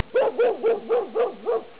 animal sounds
dog1.wav